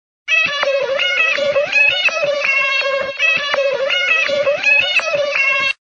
Категория: SMS-мелодии